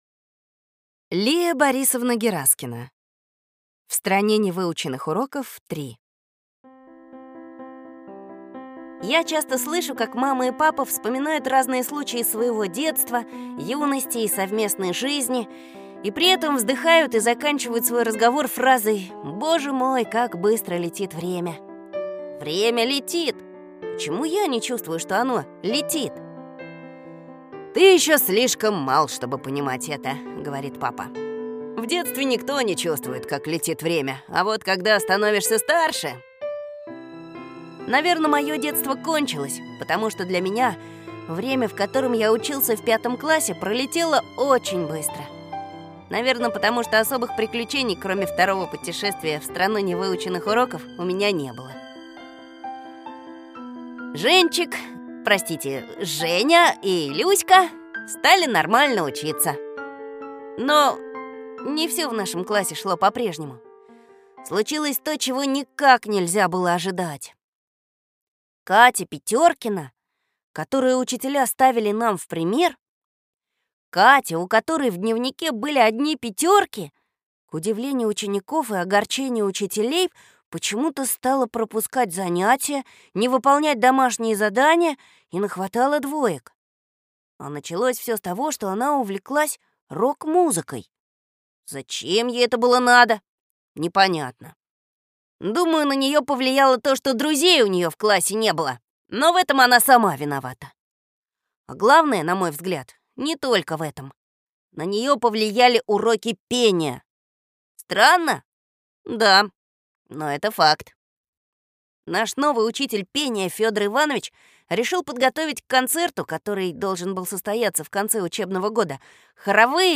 Аудиокнига Третье путешествие в Страну невыученных уроков | Библиотека аудиокниг